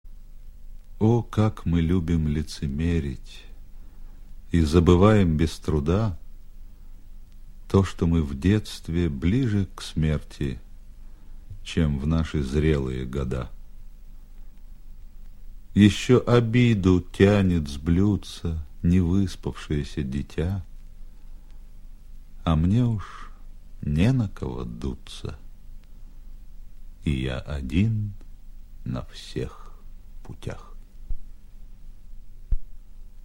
2. «Осип Мандельштам – О, как мы любим лицемерить (1932) читает Сергей Юрский» /
Mandelshtam-O-kak-my-lyubim-licemerit-1932-chitaet-Sergey-Yurskiy-stih-club-ru.mp3